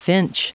Prononciation du mot finch en anglais (fichier audio)
Prononciation du mot : finch